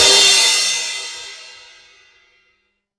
Crash Cymbal
A bright crash cymbal hit with explosive attack and long shimmering decay
crash-cymbal.mp3